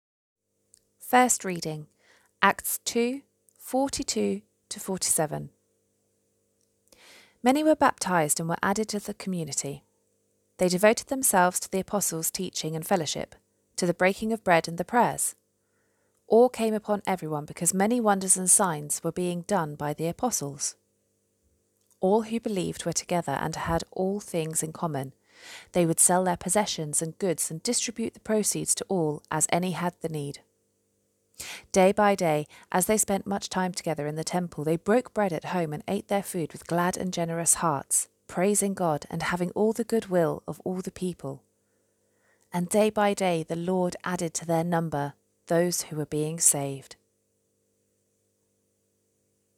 First Reading: Acts 2.42–47